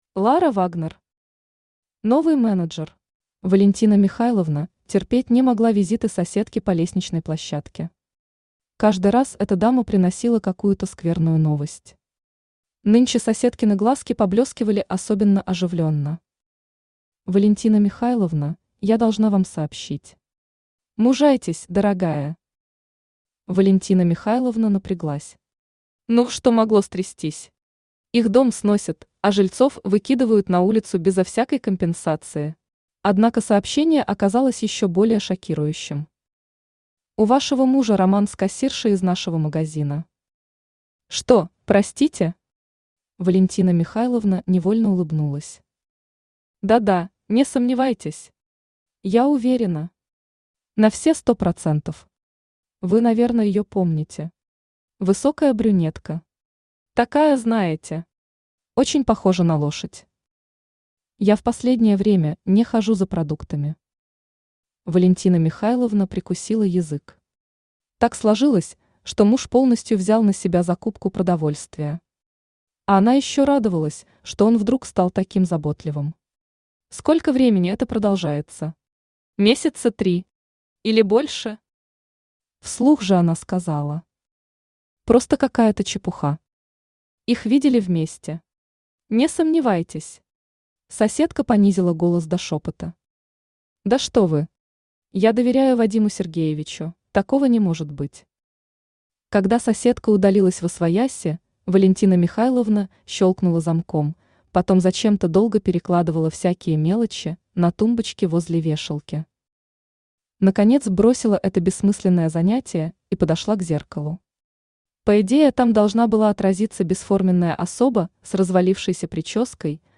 Аудиокнига Новый менеджер | Библиотека аудиокниг
Aудиокнига Новый менеджер Автор Лара Вагнер Читает аудиокнигу Авточтец ЛитРес.